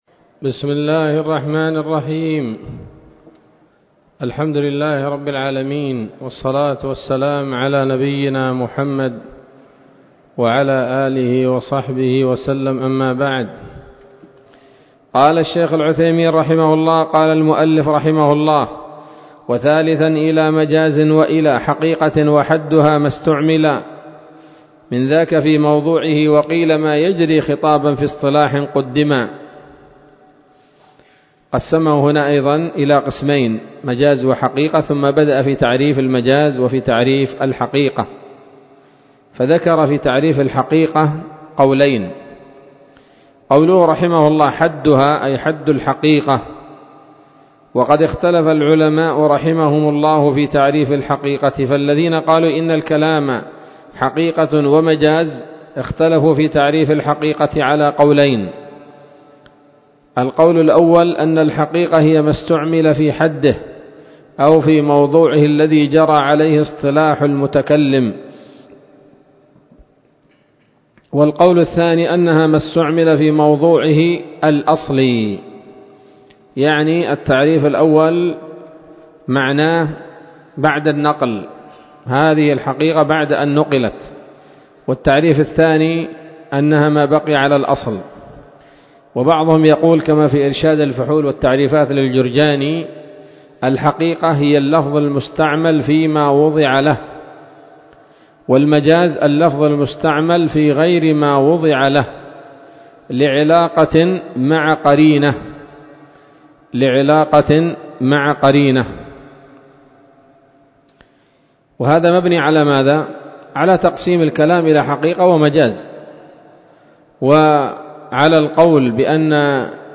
الدرس الثلاثون من شرح نظم الورقات للعلامة العثيمين رحمه الله تعالى